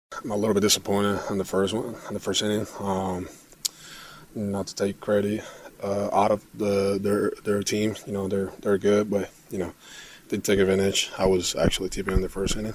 Johan Oviedo says he didn’t feel comfortable on the mound when the game started.